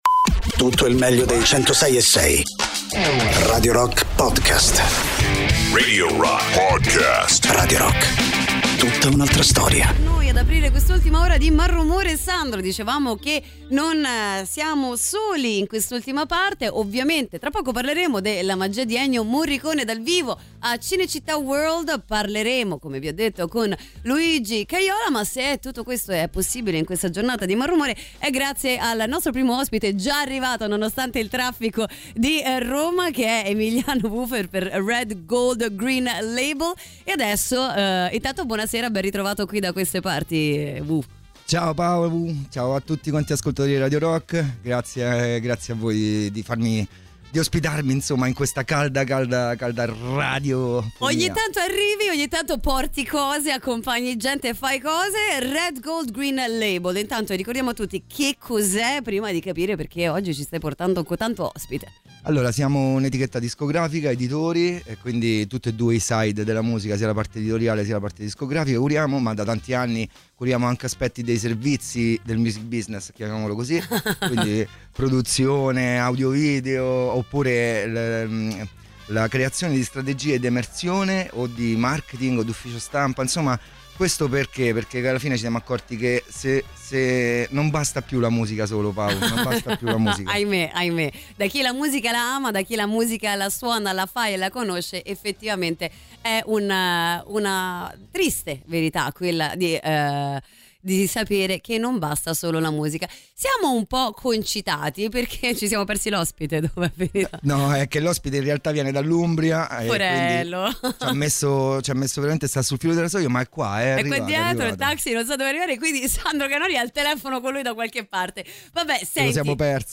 Radio Rock FM 106.6 Intervista